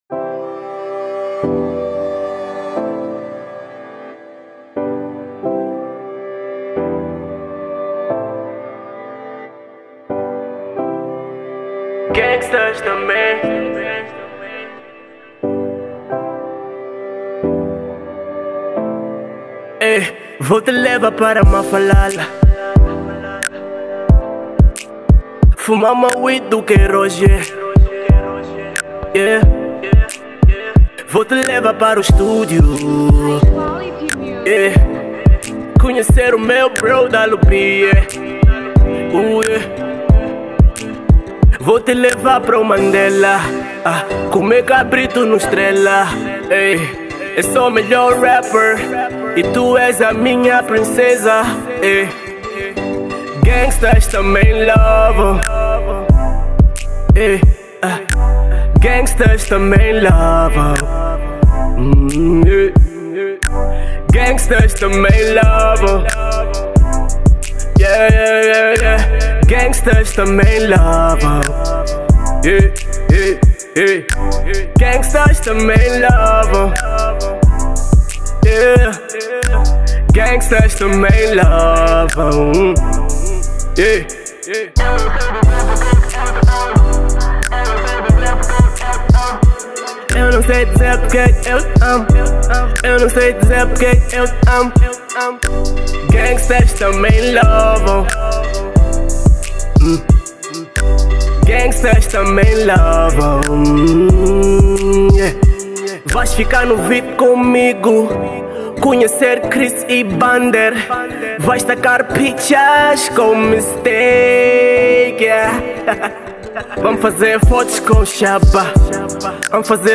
R&B
que conta com uma vibe mais romântica